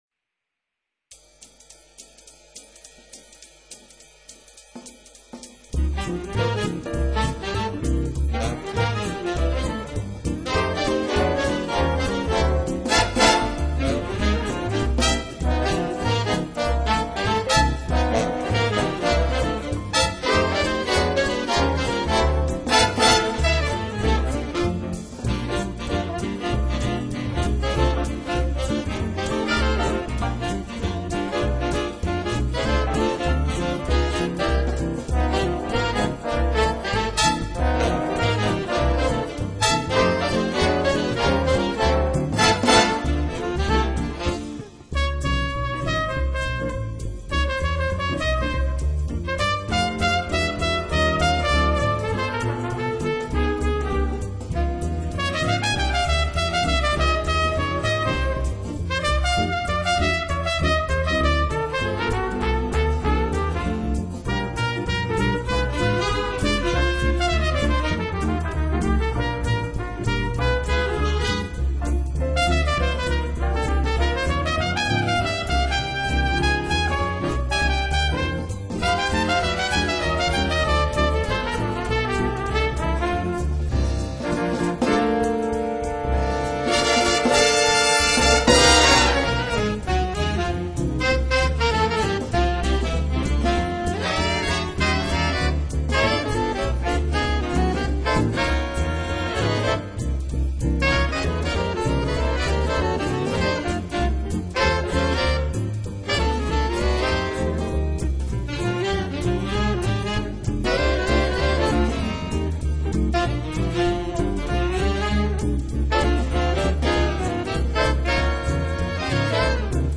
Audio clip big band